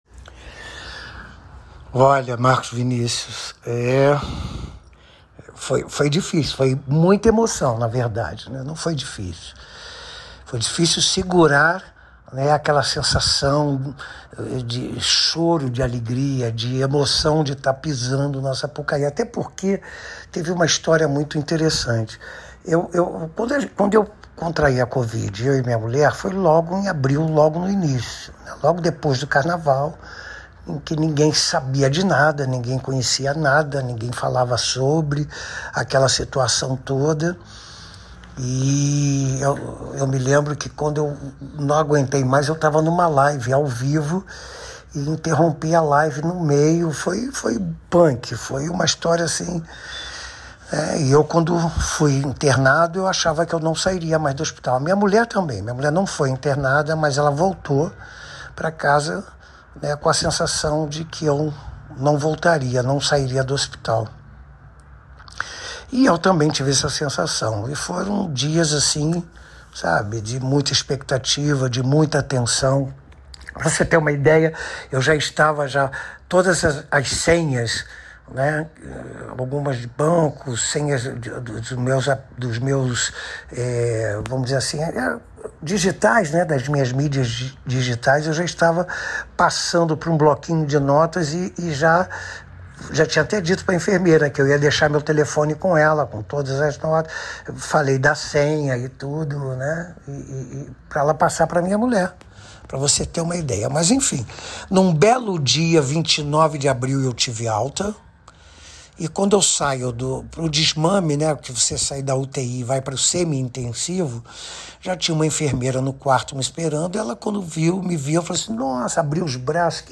Coreógrafo concedeu entrevista exclusiva ao programa Botequim do Mister